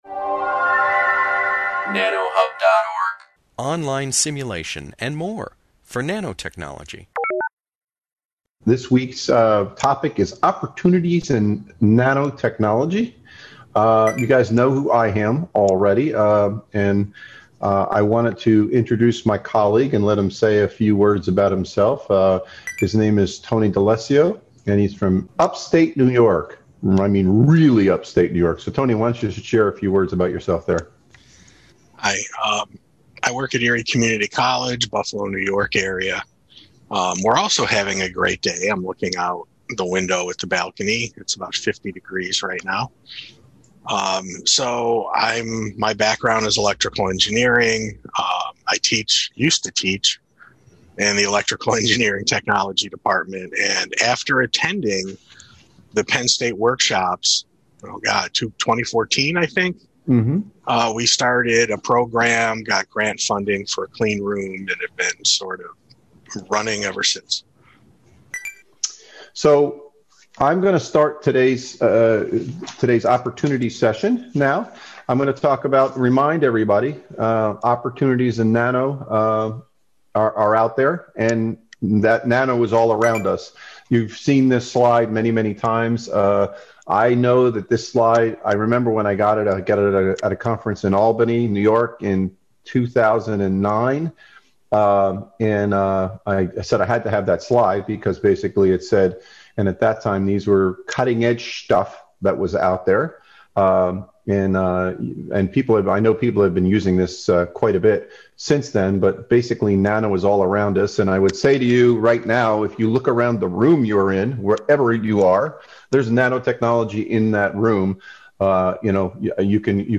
This webinar, published by the Nanotechnology Applications and Career Knowledge Support (NACK) Center at Pennsylvania State University, covers employment opportunities in different areas of nanotechnology. During the webinar, presenters discuss the nanotechnology job market, types of jobs fulfilled by nanotechnology graduates, and nanotechnology credentialing.